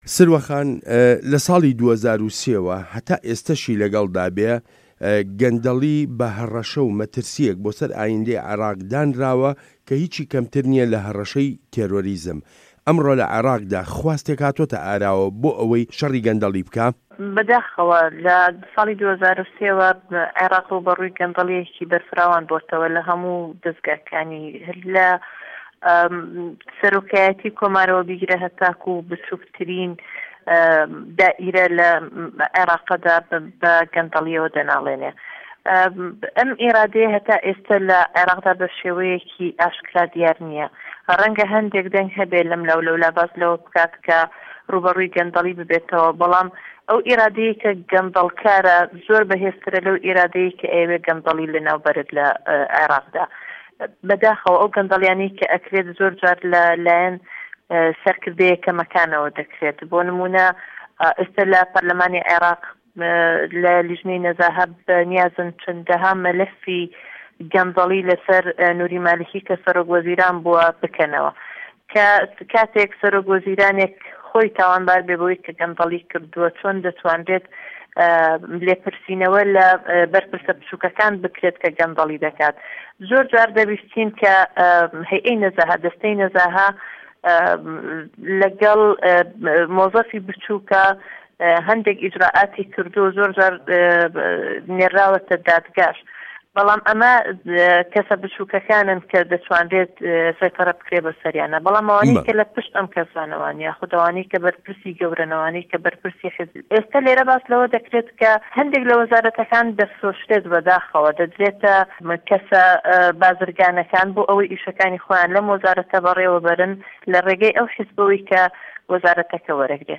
وتووێژ له‌گه‌ڵ سروه‌ عه‌بدولواحید